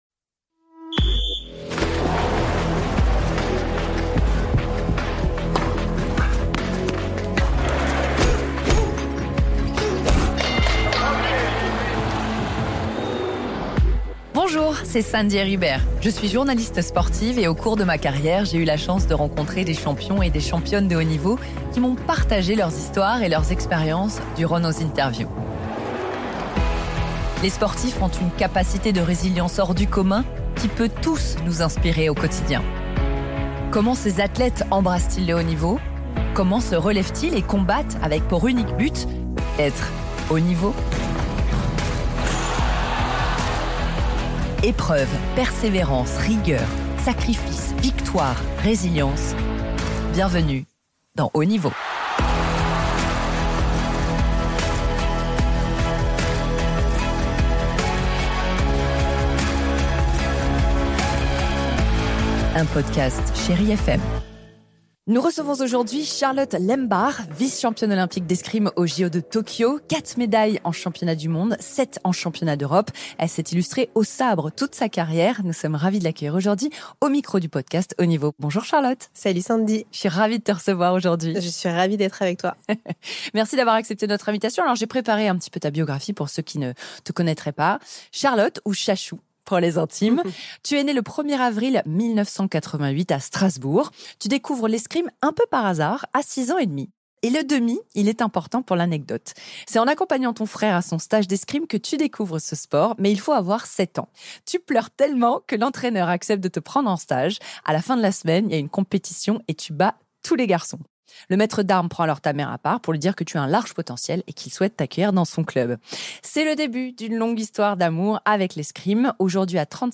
Dans ce deuxième épisode, Sandy Heribert reçoit Charlotte Lembach.